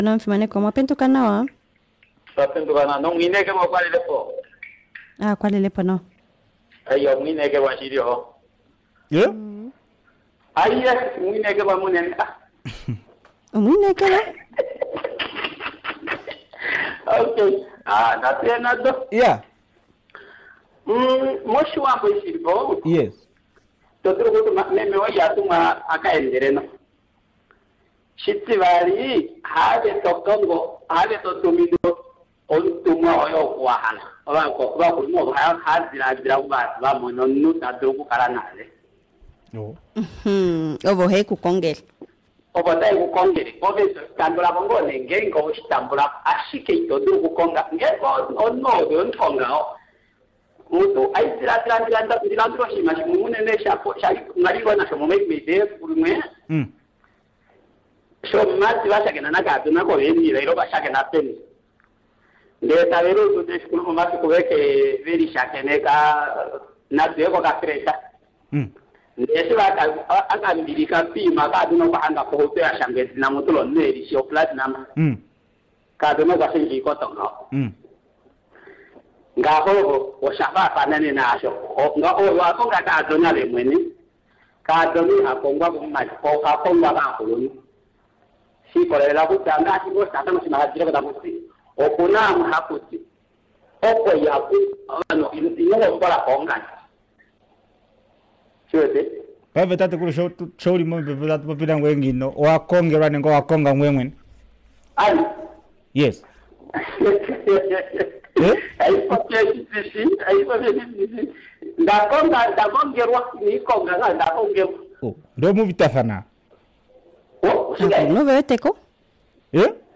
Omulumentu okwapopi mombepo tati , ye okwakongelwa omukadhona kaakuluntu ye ashike hole so nice tatekulu, ando aniwa okwali ngaa tashi ningilwa kehe omulumentu neeg ,